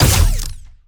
GUNAuto_Plasmid Machinegun Single_02_SFRMS_SCIWPNS.wav